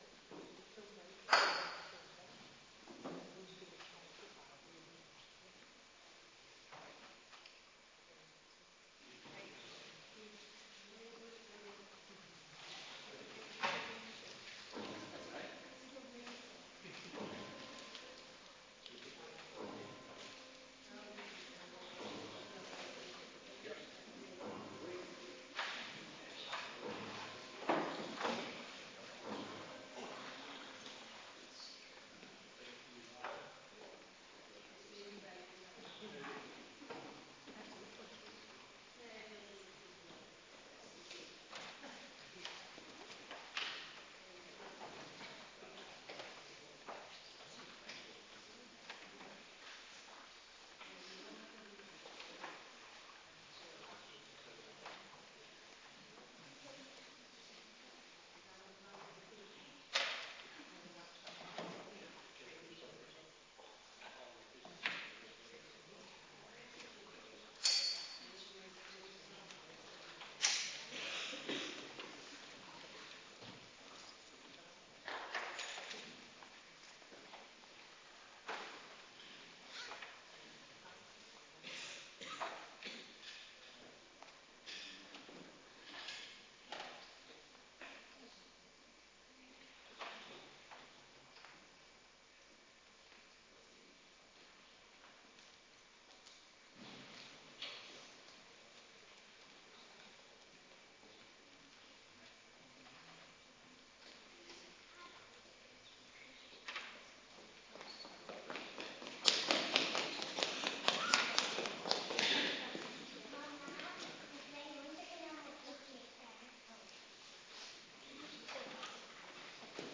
Adventkerk Dinsdag week 14
Avondgebed Onderwerp “Als een graankorrel”